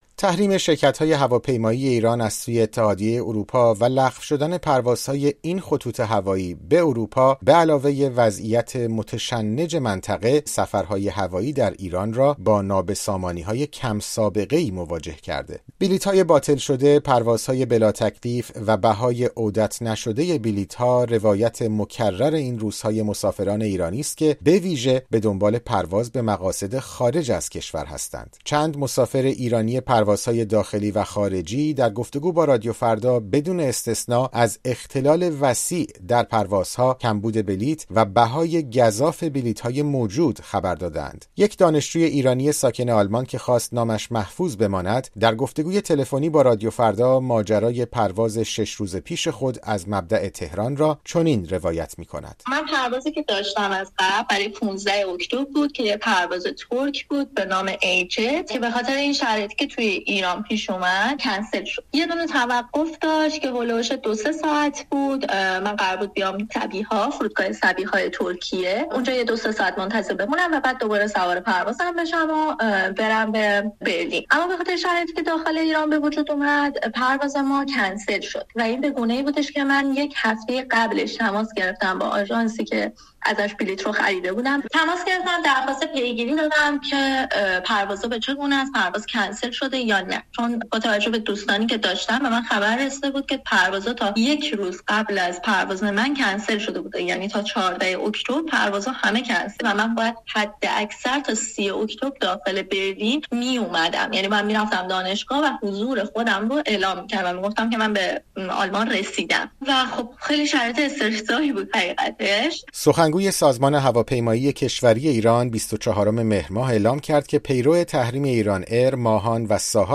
گزارش رادیویی درباره «نابسامانی» در پروازهای مسافربری ایران